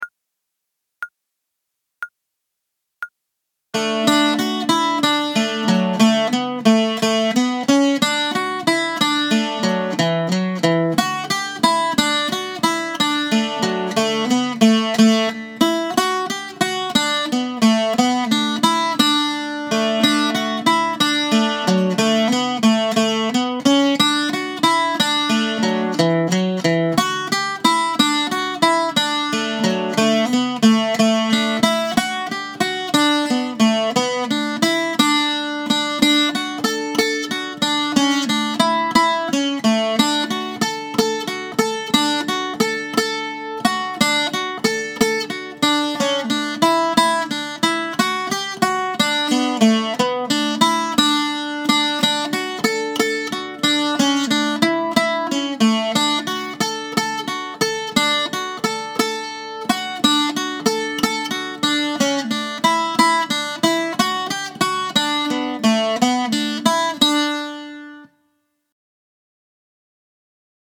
• Stile: irlandese